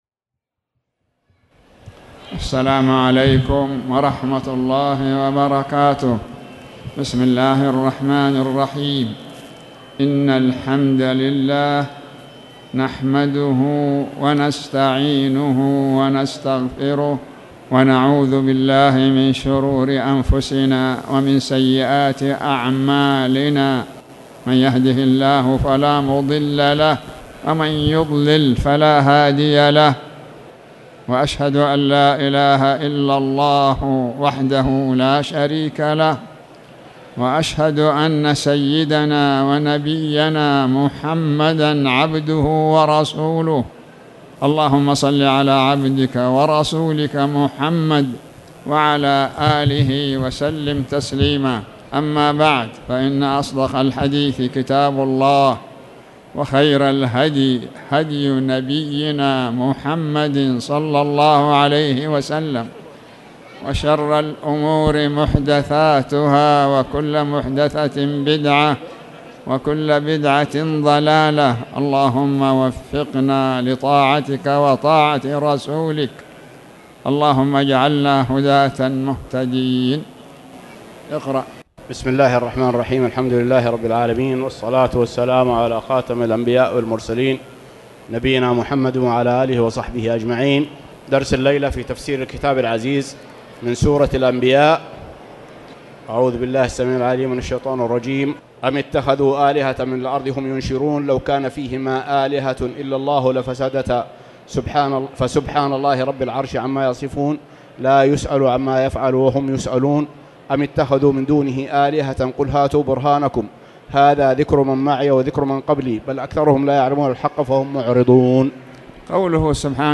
تاريخ النشر ٣ ذو القعدة ١٤٣٨ هـ المكان: المسجد الحرام الشيخ